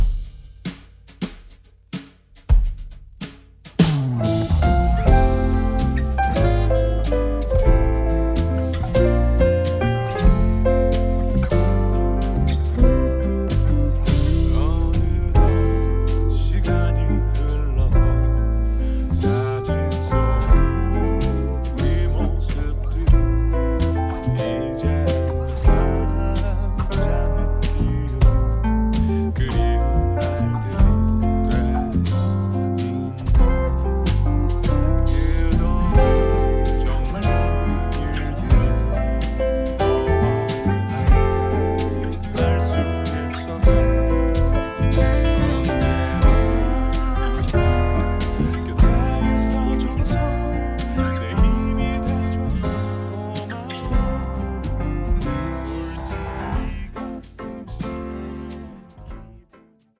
음정 -1키 4:22
장르 가요 구분 Voice Cut